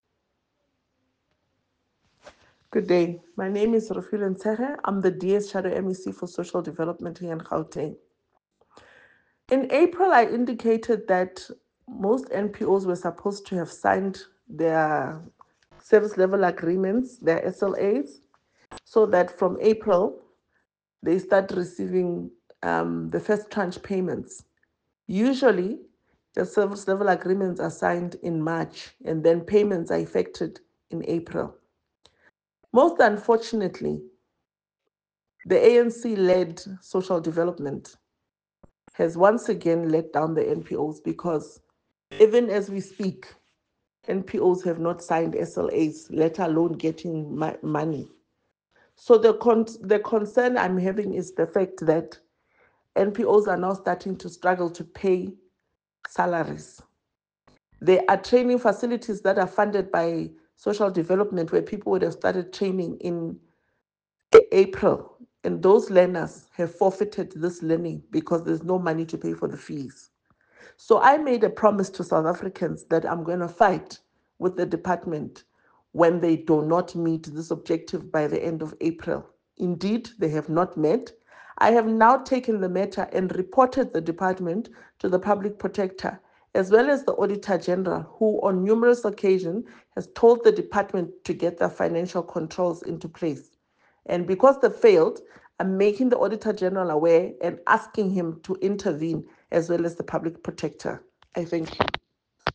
Sesotho soundbites by Refiloe Nt’sekhe MPL.